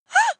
Gasp 2.wav